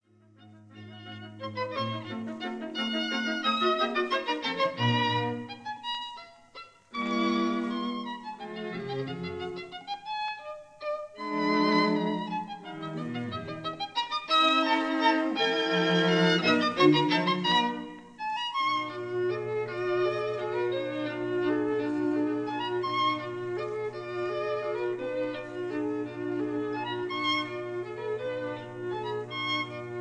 This is the classic 1938 recording
violin
viola
cello